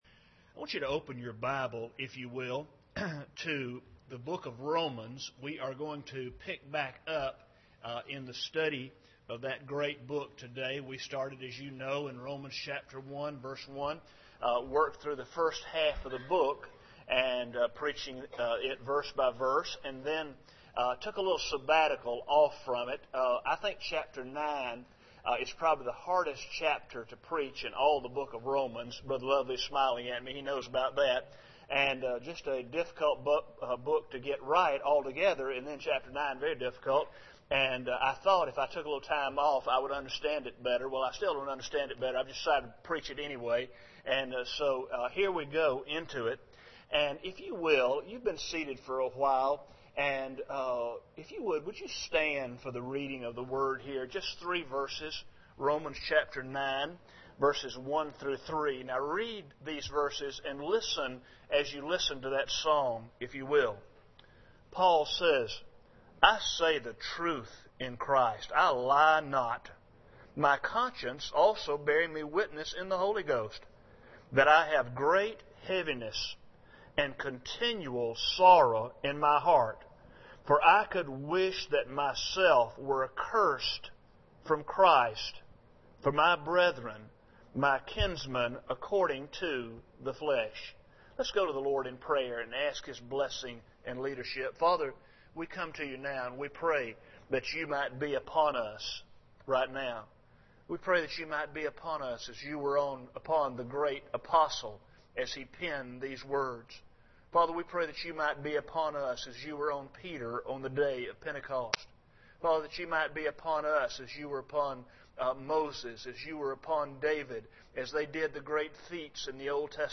New Year’s Sermon
Service Type: Sunday Morning